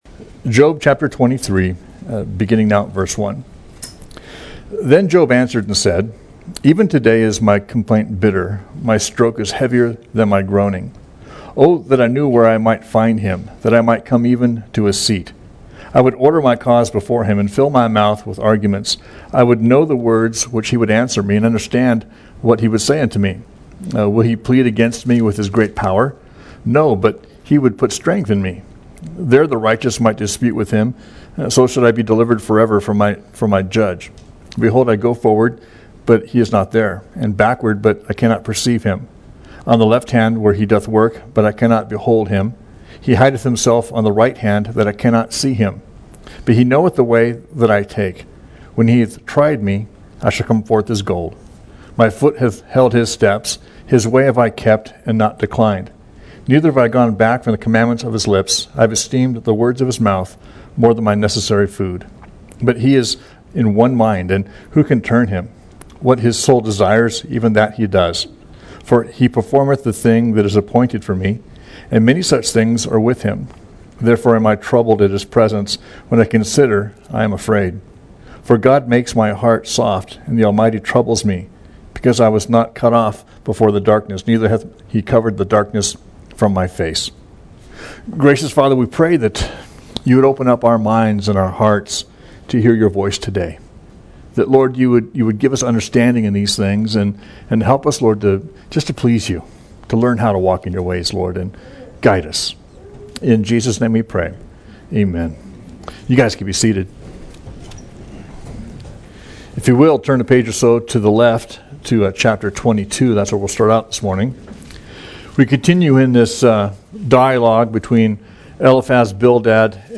We livestream all of our services on FaceBook.